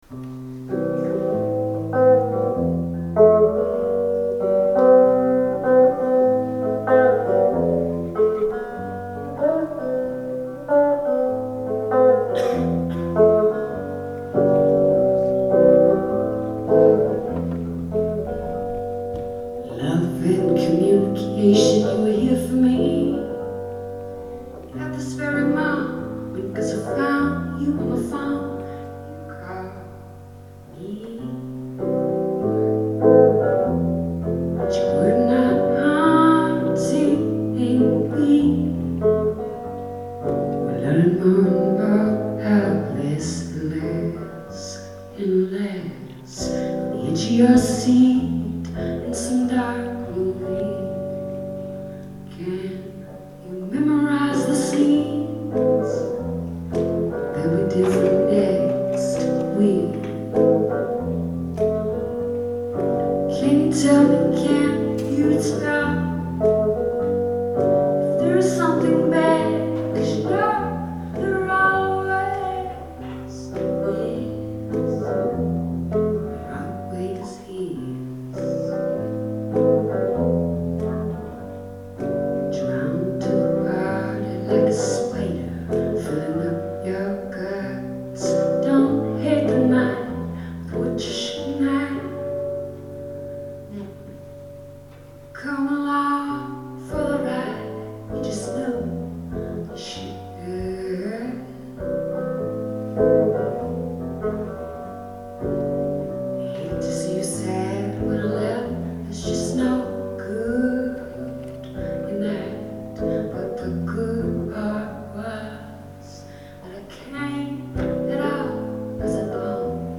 Live at the Museum of Fine Arts Boston